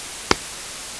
clap-detection
clap-03_noise0.02.wav